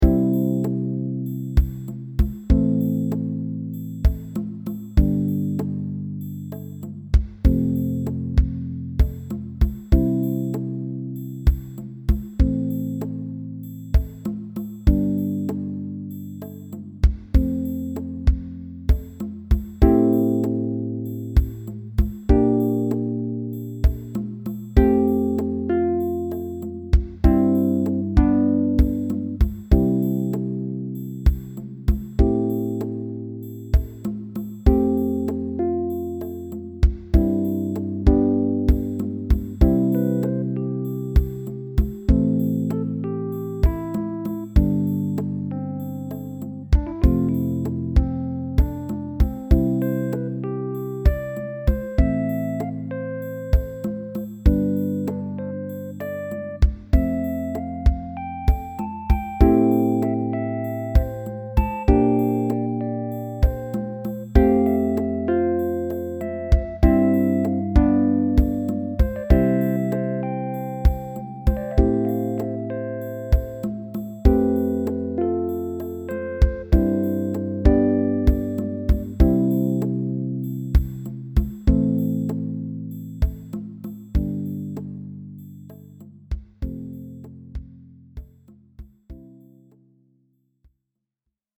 I made this track as accompaniment for the ballad to end a friend’s stage show.